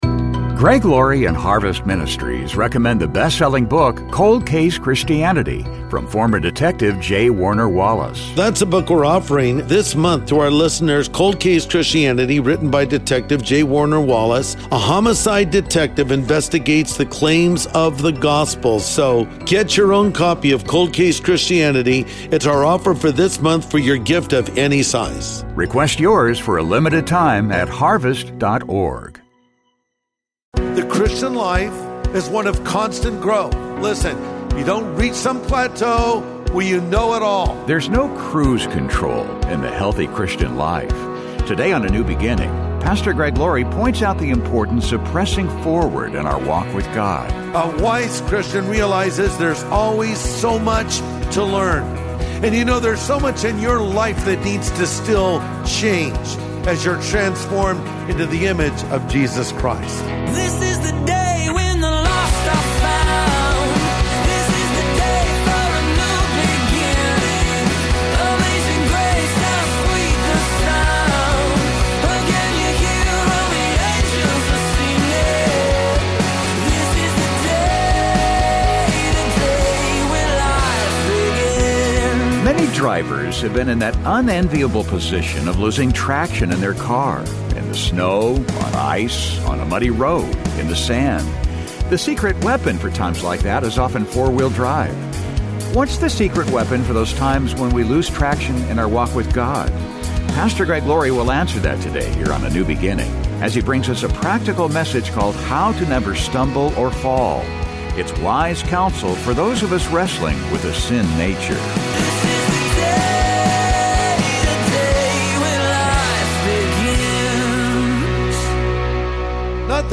Pastor Greg Laurie will answer that today here on A NEW BEGINNING as he brings us a practical message called How to Never Stumble or Fall.